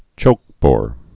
(chōkbôr)